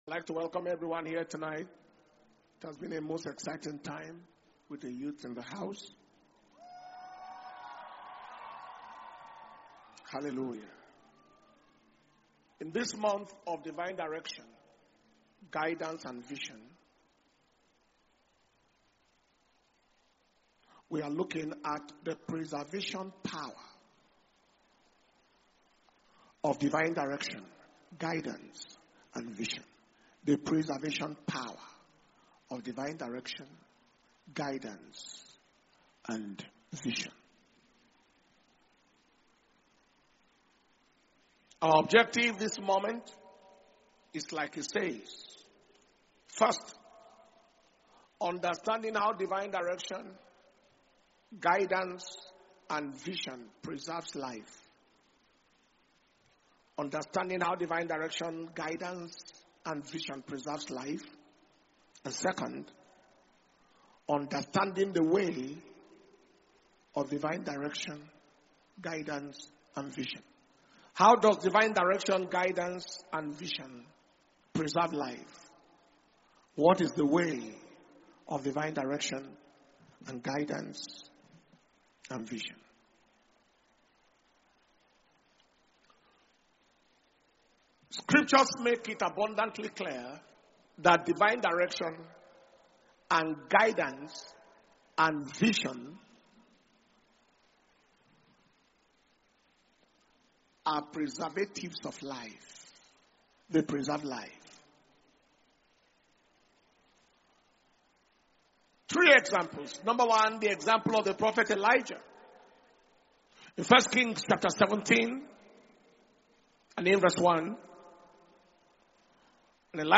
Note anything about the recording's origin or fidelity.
Preservation And Power Communion Service